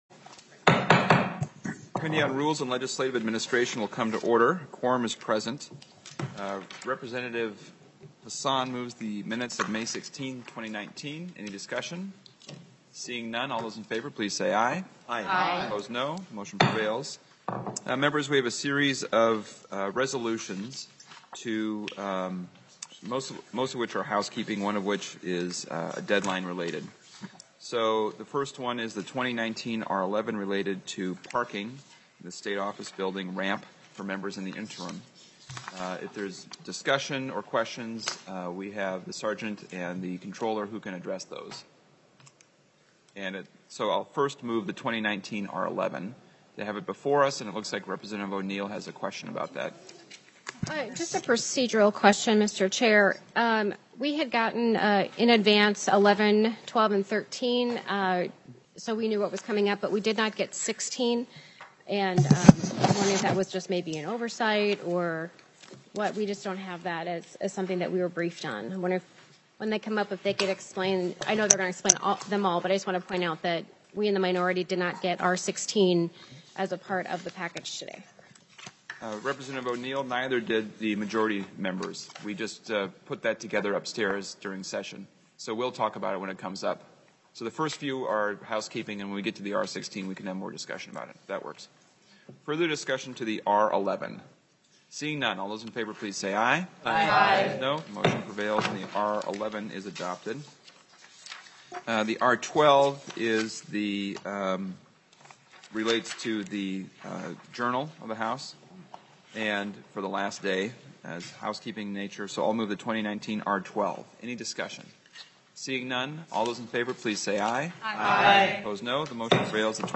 Chair: Rep. Ryan Winkler
Meeting: